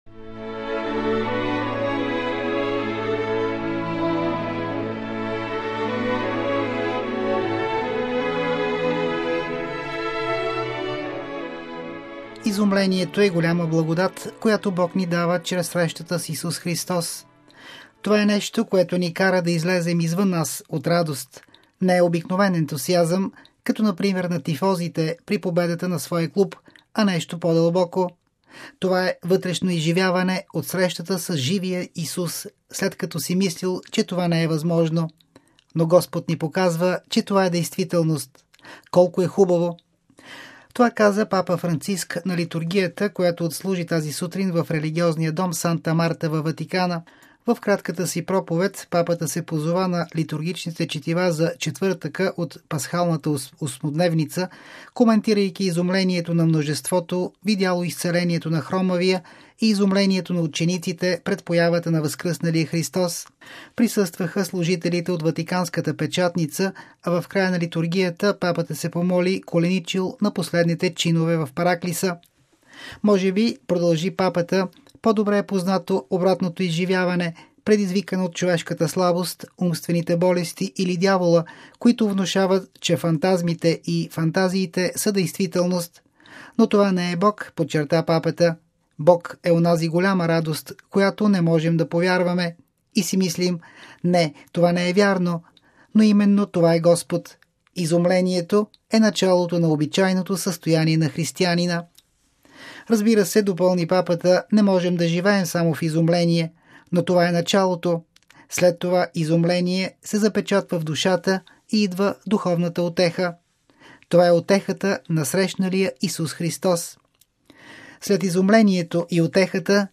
Това каза Папа Франциск на литургията, която отслужи тази сутрин в религиозния дом Санта Марта във Ватикана.